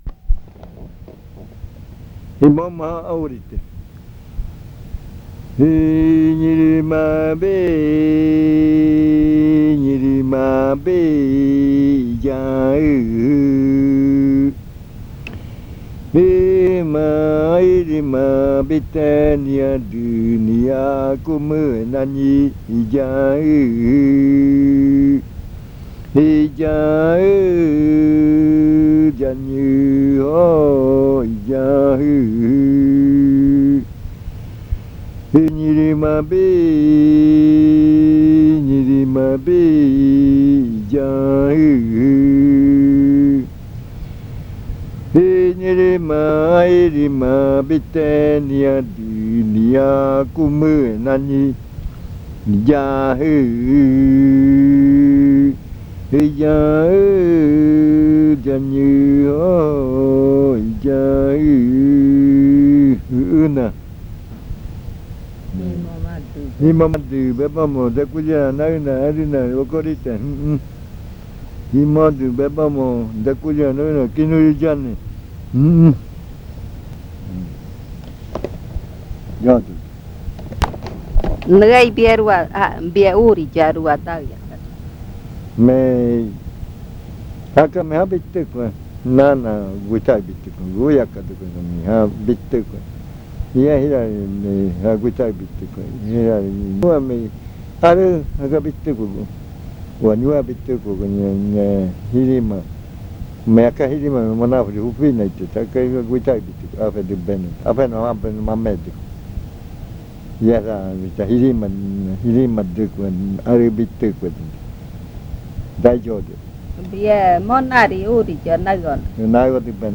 Leticia, Amazonas
Canción hablada (uuriya rua).
Spoken chant (uuriya rua). Come bird jirima.